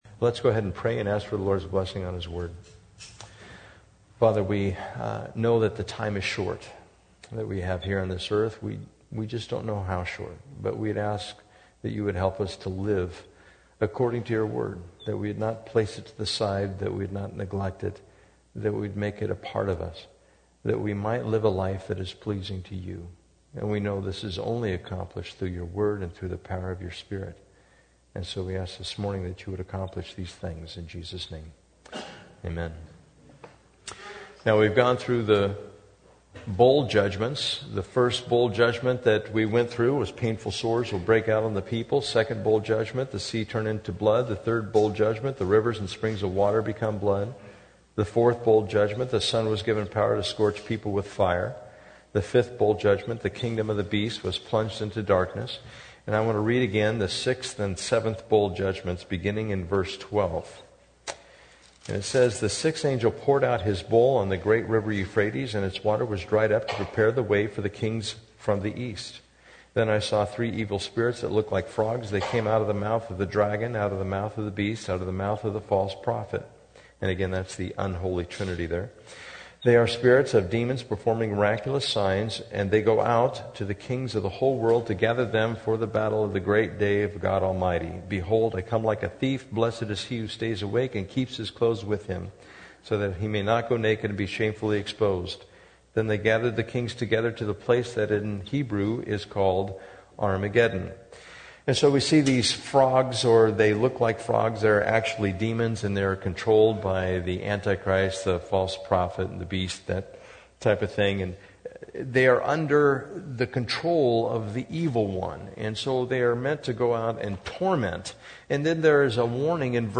Bible Text: Revelation 17:1-18 | Preacher